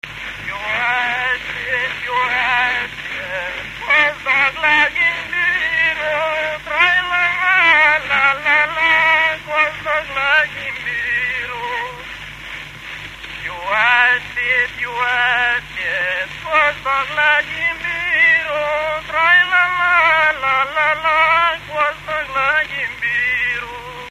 Moldva és Bukovina - Moldva - Bogdánfalva
ének
Stílus: 8. Újszerű kisambitusú dallamok
Szótagszám: 6.6.6.6
Kadencia: V (3) X 1